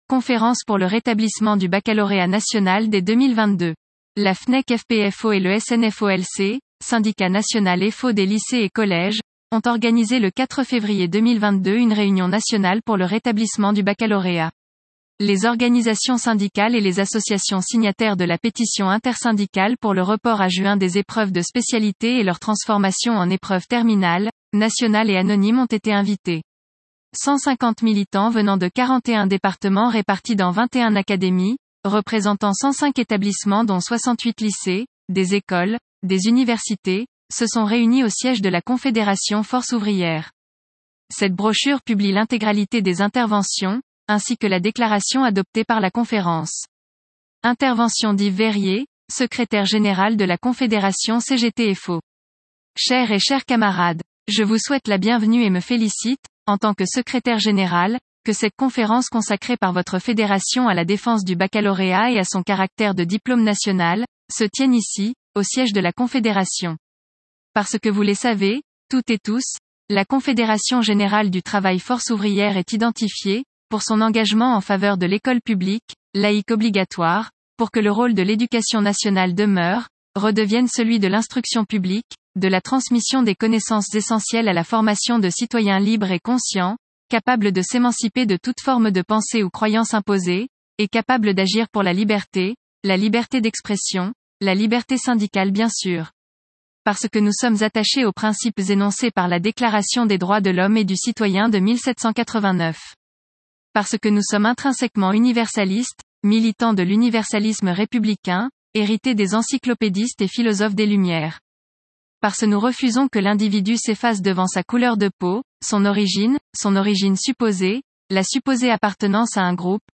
La FNEC FP-FO et le SNFOLC (syndicat national FO des Lycées et Collèges) ont organisé le 4 février 2022 une réunion nationale pour le rétablissement du baccalauréat.
Intervention d’Yves Veyrier, secrétaire général de la Confédération cgt-FO